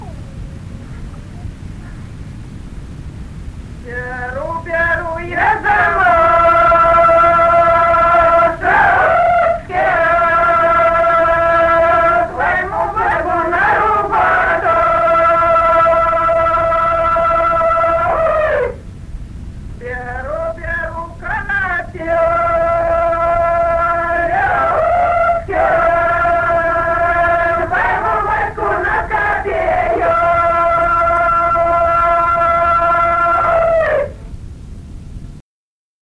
Народные песни Стародубского района «Беру, беру я замашечки», прополочная.
с. Курковичи.